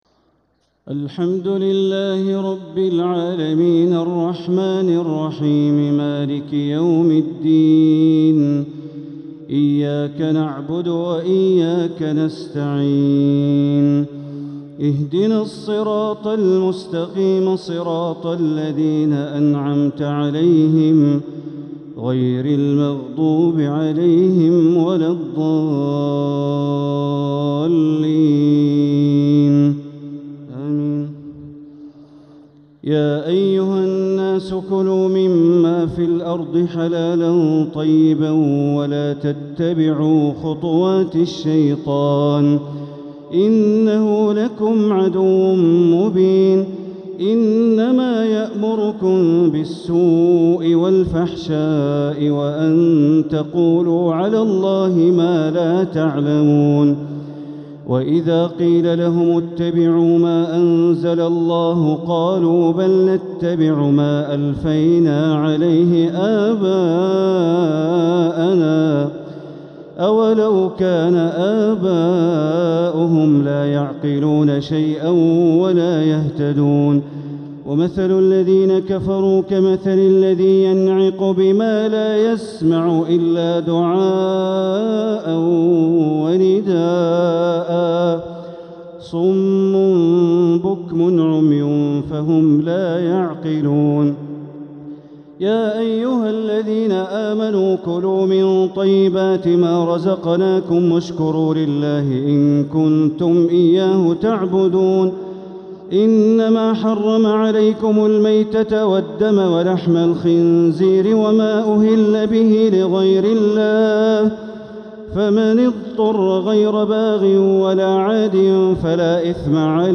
تراويح ليلة 2 رمضان 1447هـ من سورة البقرة (168-203) > تراويح 1447هـ > التراويح - تلاوات بندر بليلة